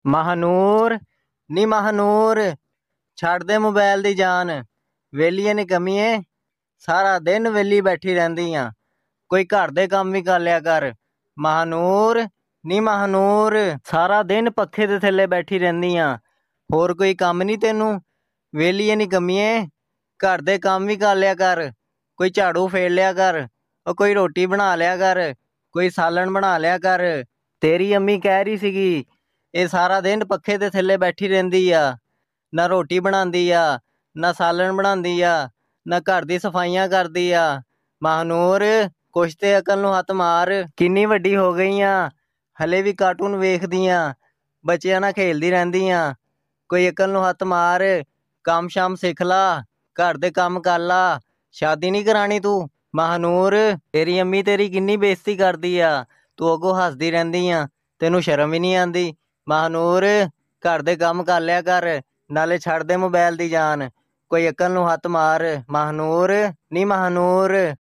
Goat Calling